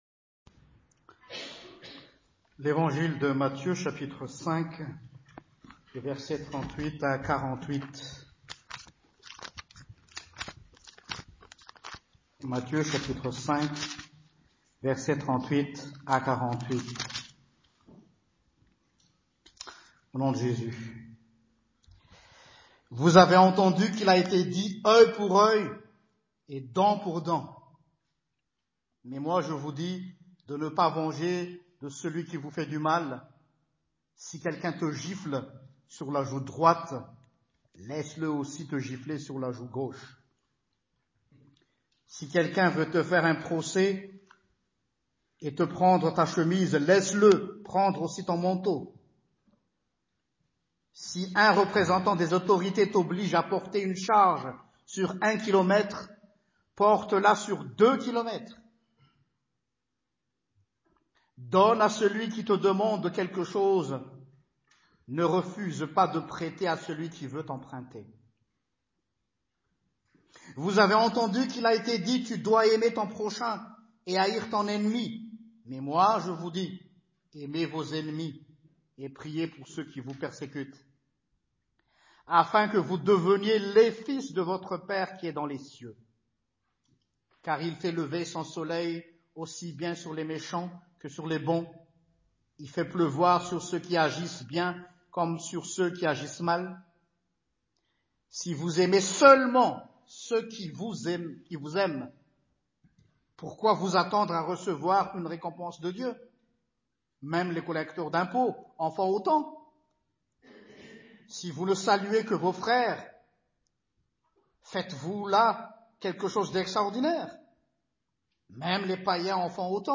L’AMOUR! UNE LOI HORS NORME ET HORS PARADIGME (Prédication du 23 Février 2020)
Prédication-du-22-Février.mp3